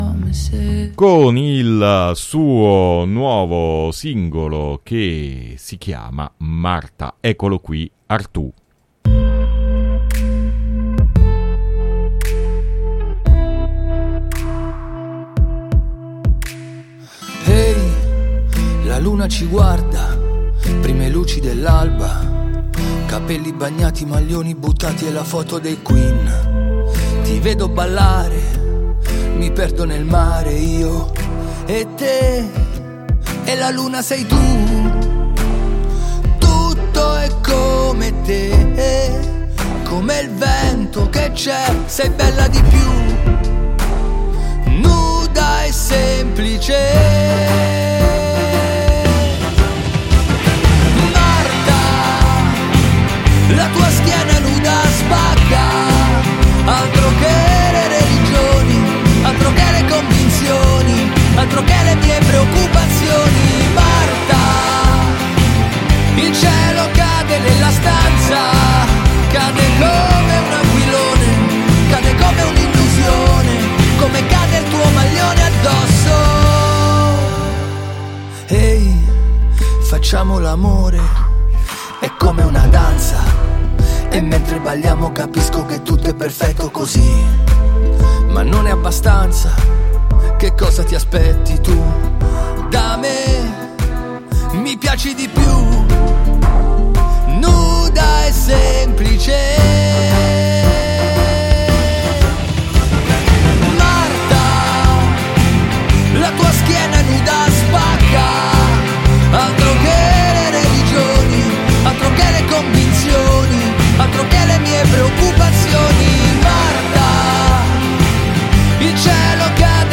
Intervista e live set acustico